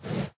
sounds / mob / horse / breathe3.ogg
breathe3.ogg